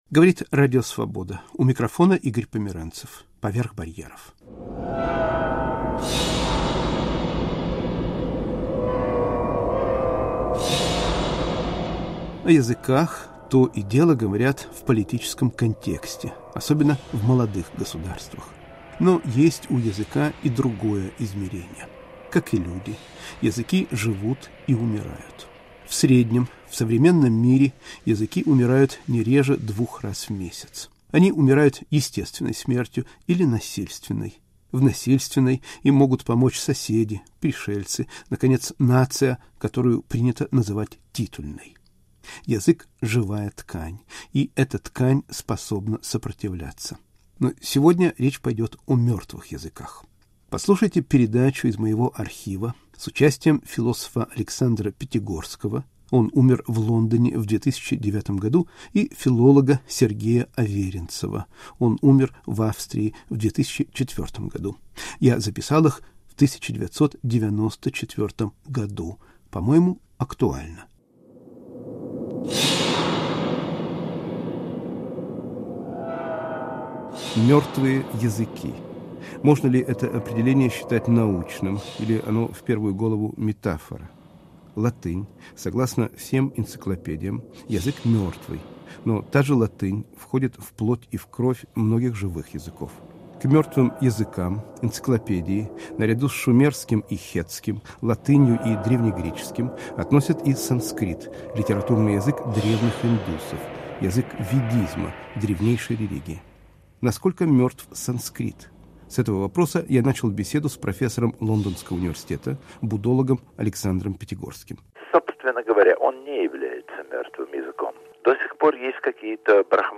Из архива Поверх барьеров. В передаче звучат голоса С.Аверинцева и А.Пятигорского